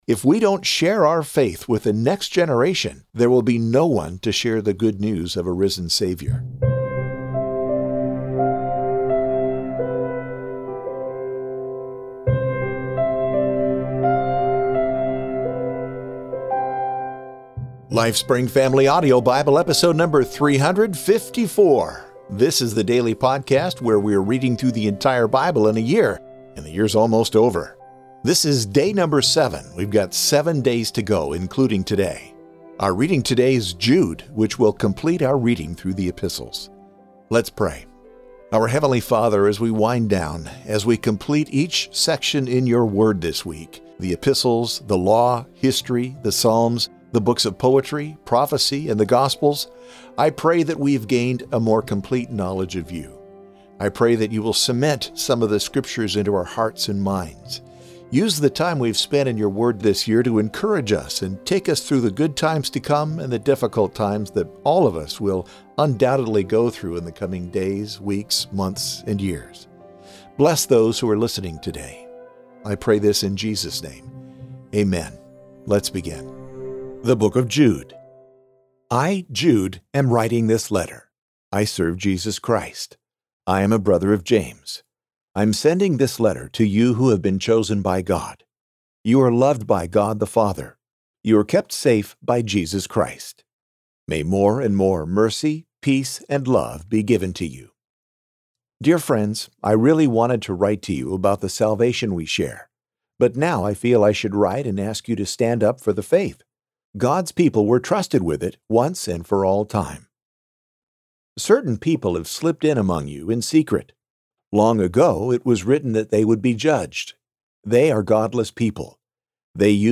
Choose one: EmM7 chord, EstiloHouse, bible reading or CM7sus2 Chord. bible reading